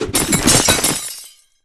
ice_spell_impact_icicle_hits1.wav